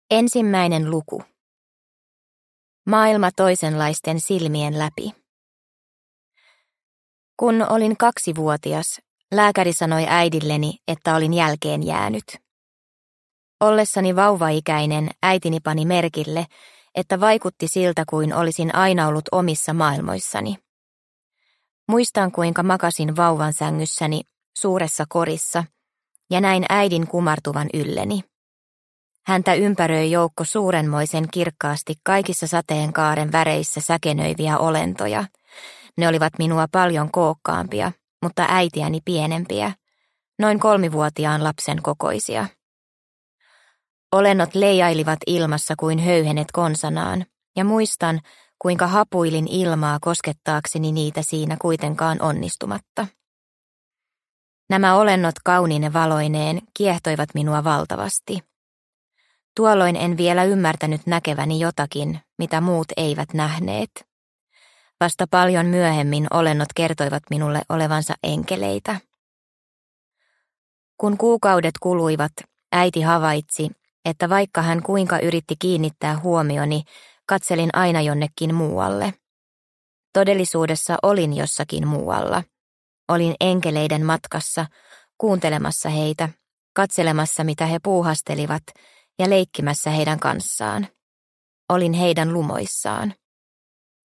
Enkeleitä hiuksissani – Ljudbok – Laddas ner